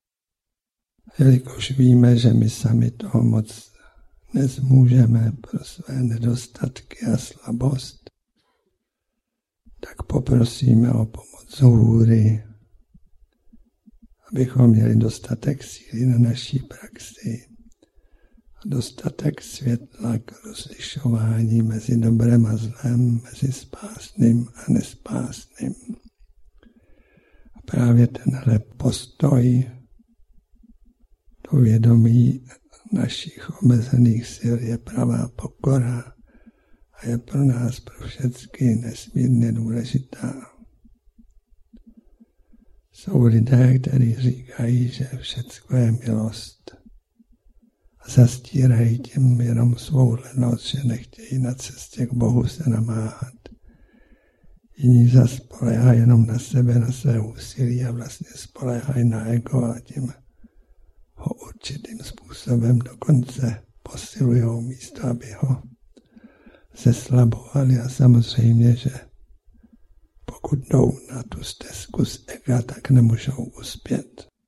Promluvy a úvahy. Záznam promluv, které předcházely nebo provázely meditace přátel na společných setkáních. Nejedná se o studiovou nahrávku.